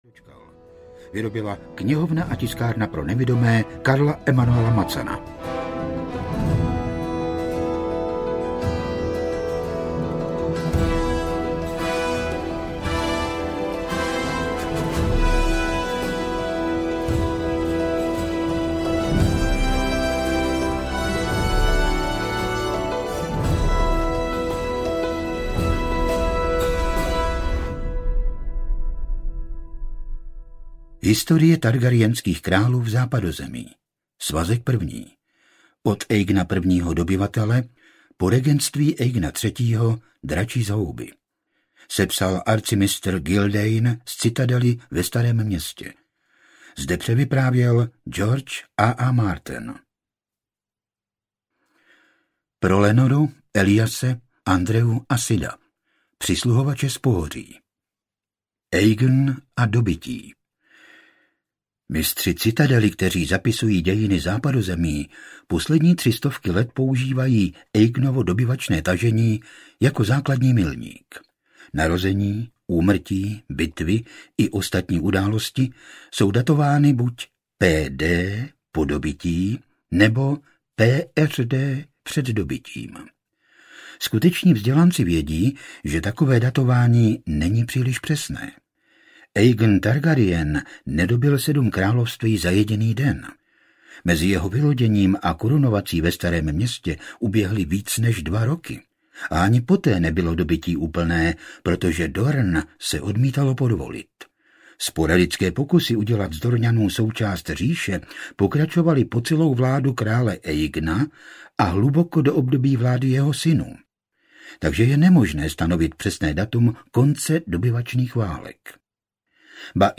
Oheň a krev audiokniha
• InterpretFrantišek Dočkal